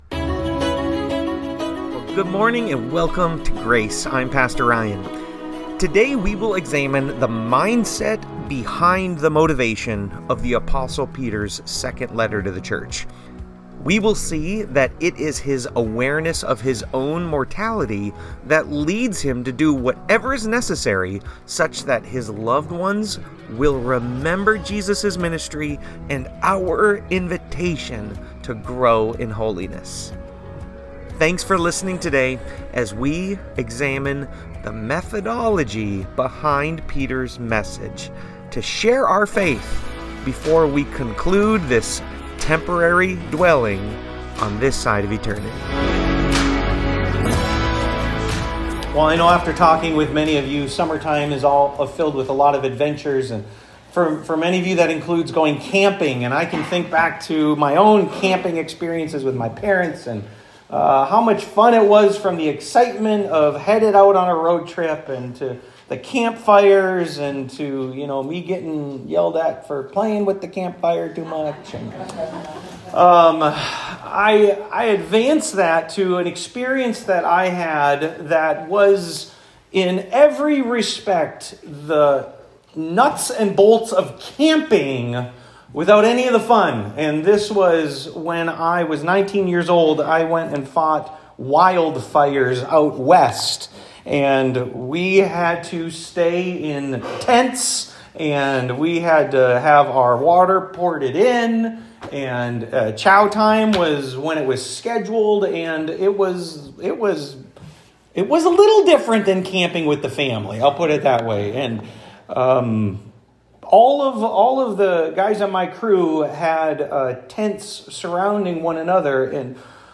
How to Die – Peter – Grace Church